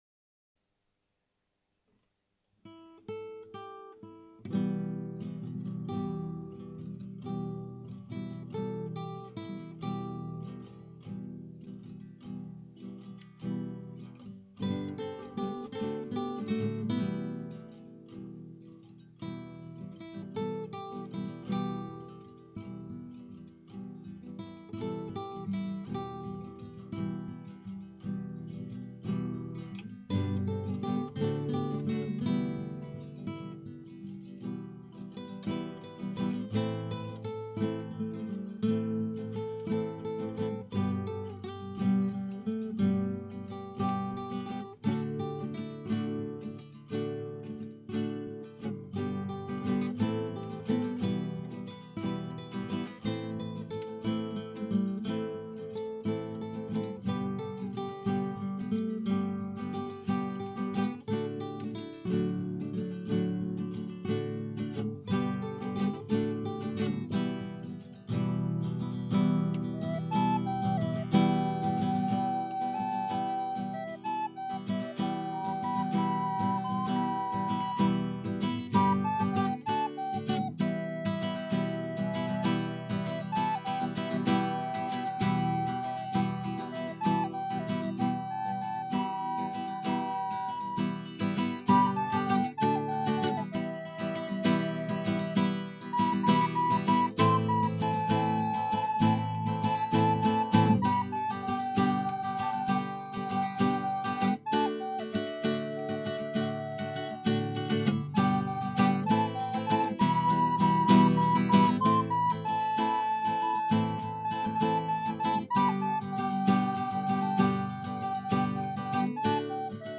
עדינות לשמה :)
עדין ומיוחד.
(ואם שמיעתי לא נשתבשה עלי) - אכן כן, נוגן בחלילית וגיטרה.